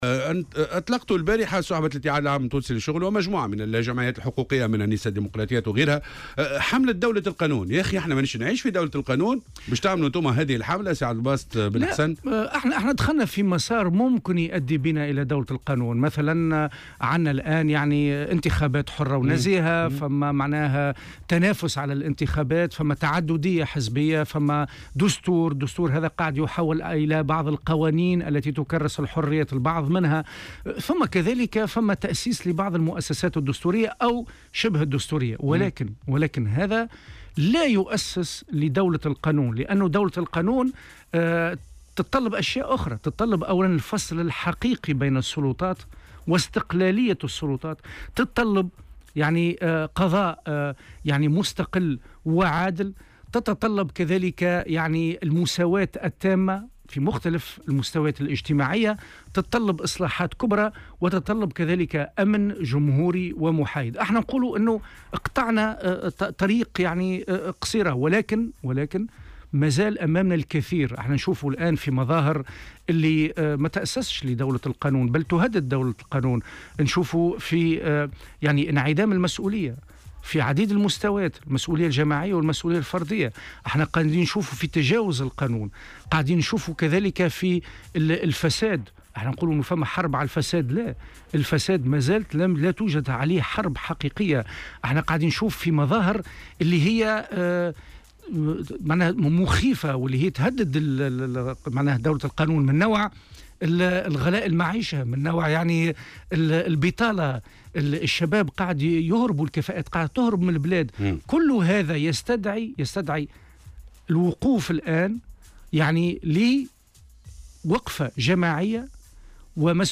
في مداخلة له اليوم في برنامج "صباح الورد" على "الجوهرة أف أم"